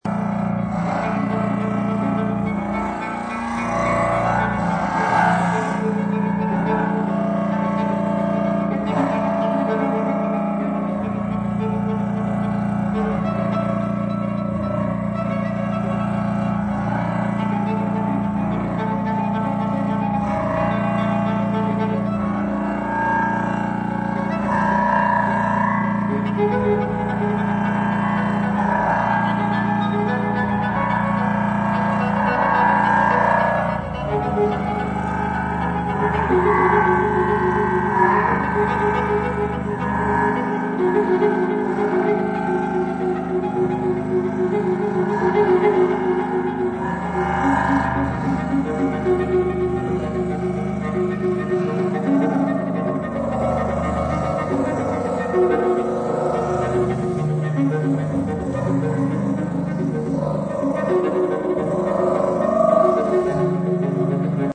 violin, interactive violin bow